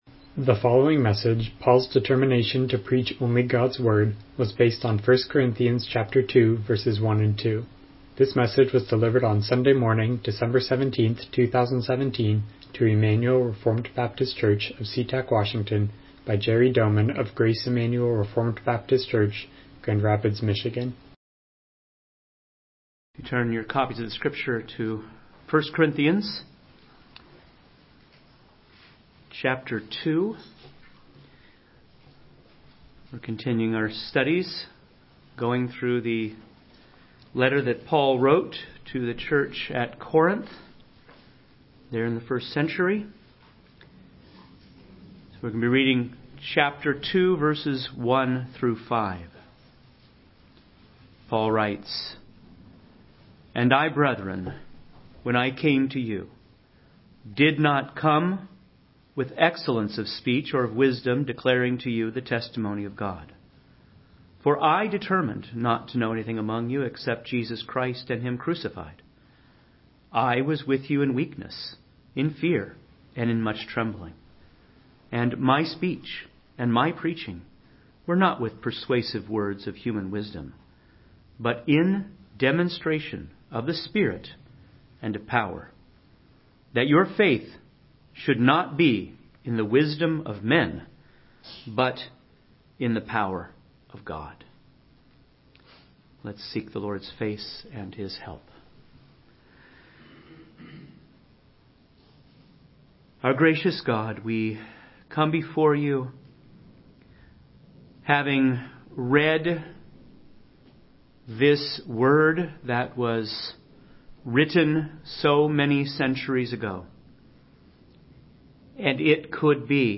Passage: 1 Corinthians 2:1-2 Service Type: Morning Worship